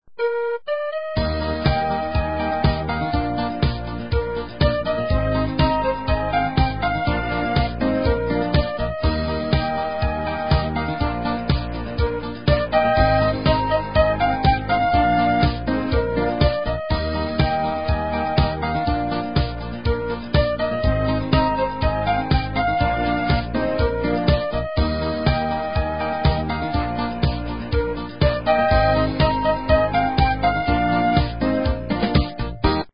Polyphonic Ringtones